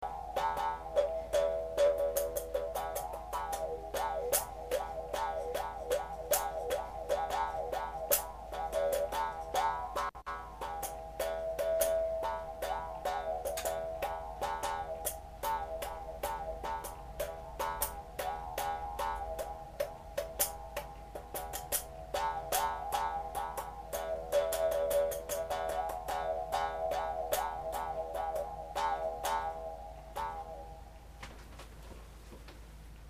口琴+ちびびよん♪